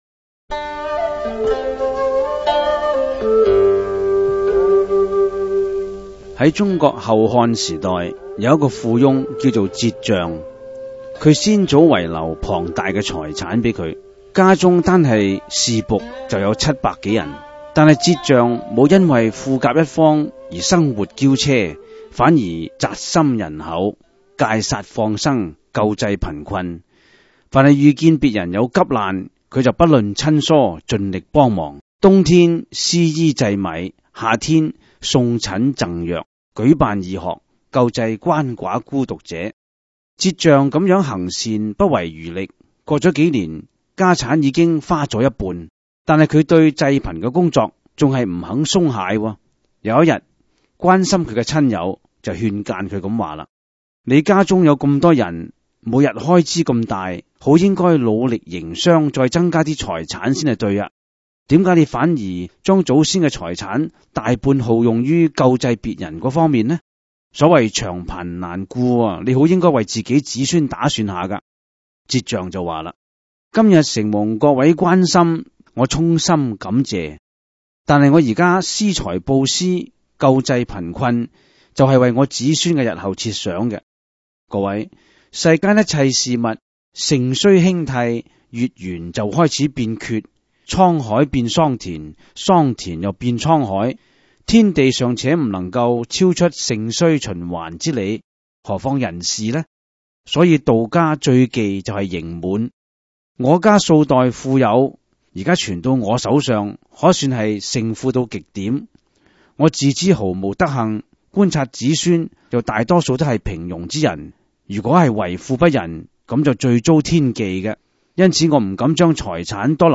第 十 五 辑       (粤语主讲  MP3 格式)